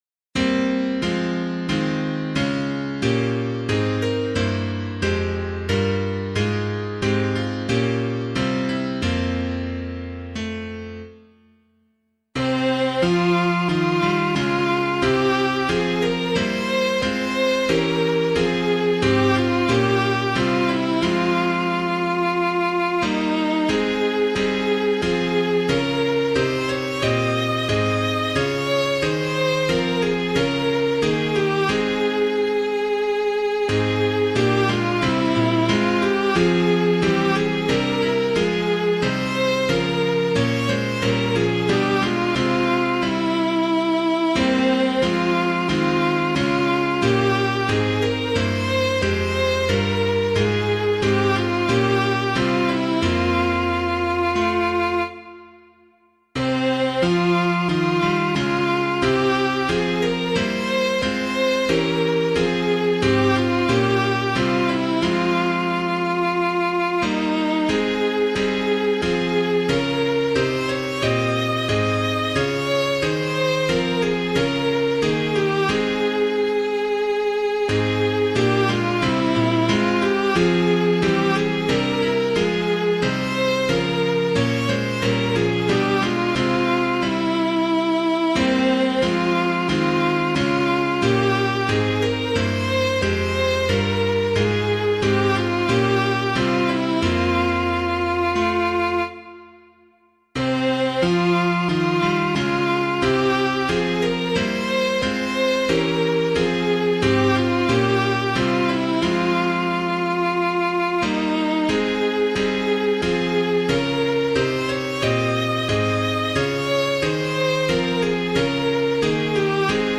piano
Soft Echoes of the Angels' Song [Joncas - HALIFAX] - piano.mp3